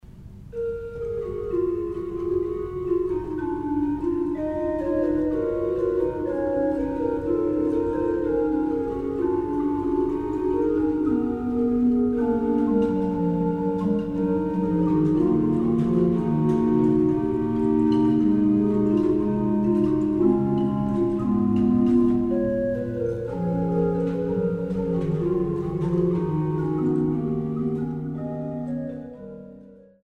an der Renkewitz-Orgel auf Schloss Augustusburg